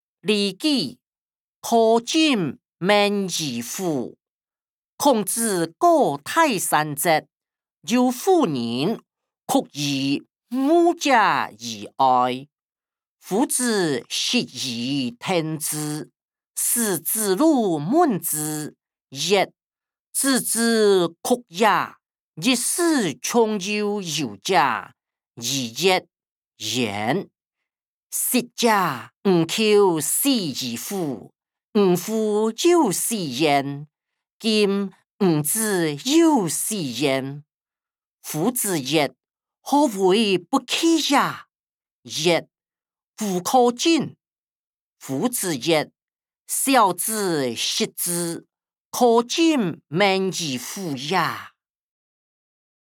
經學、論孟-苛政猛於虎音檔(大埔腔)